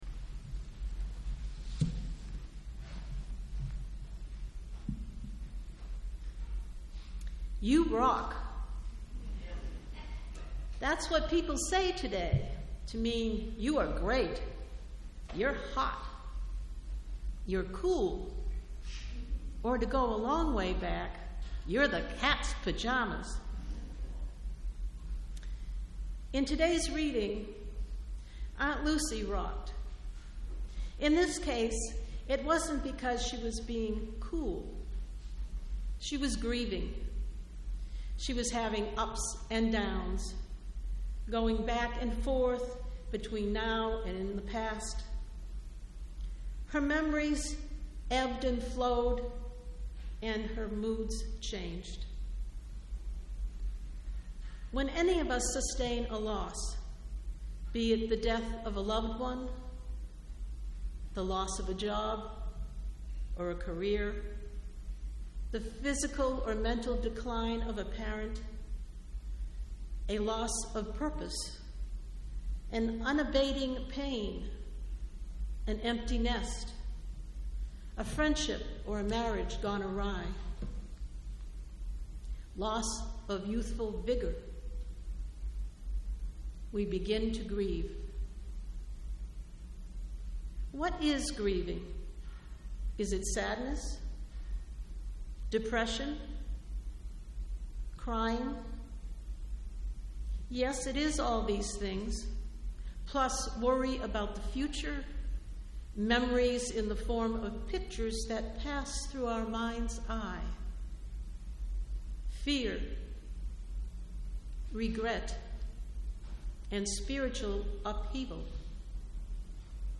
The sermon, Rocking Time, is about how we can grieve healthfully and how we can support one another during a time of mourning.